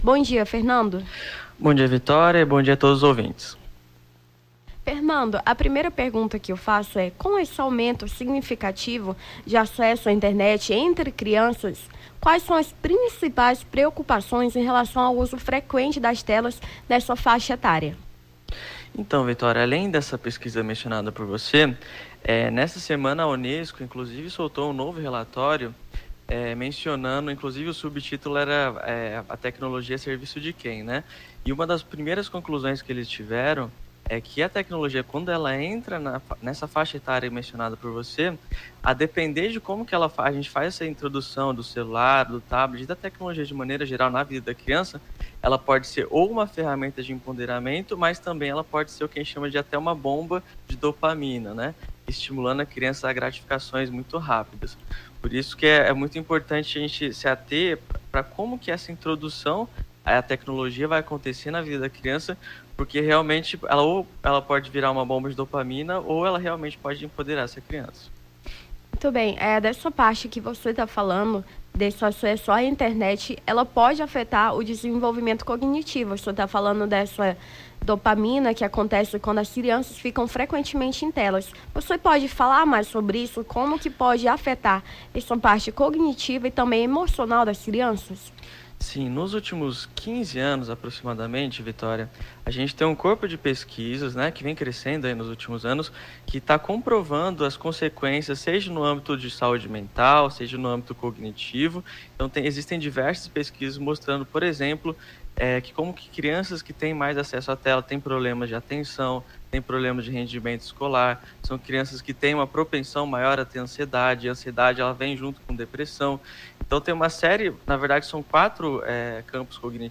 Nome do Artista - CENSURA - ENTREVISTA (DESCANSO DIGITAL) 01-08-23.mp3